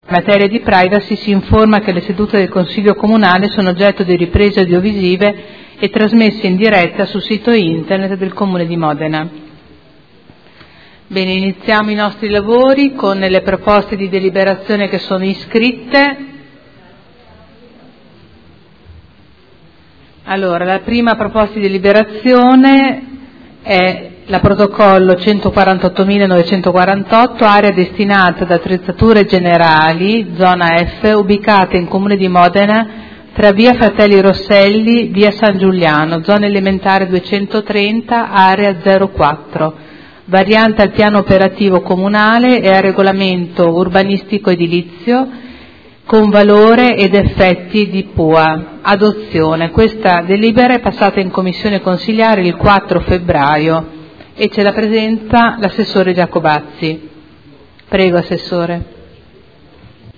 Apertura lavori del Consiglio Comunale